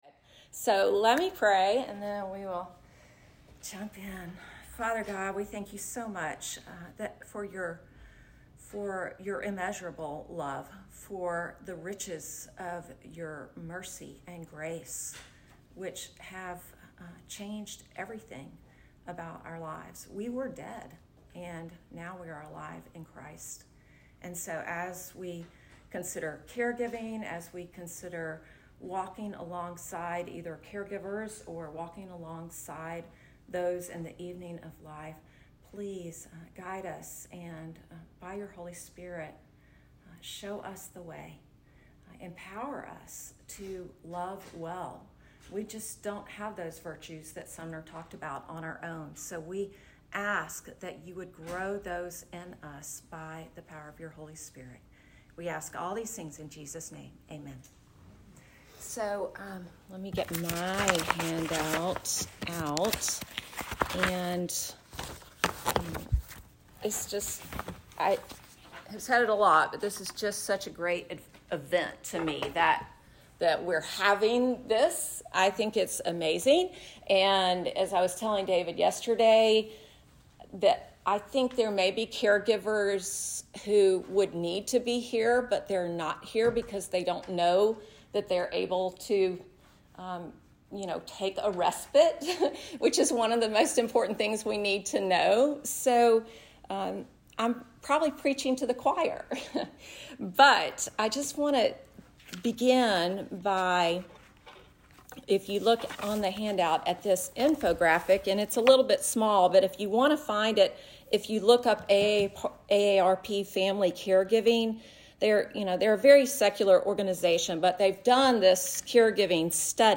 Breakout Session: Gospel Comfort for Caregivers